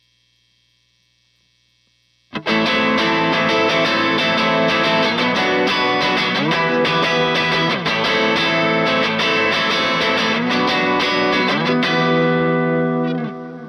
Seattle_90bpm_Amin
Guitar_Seattle_90bpm_Amin.wav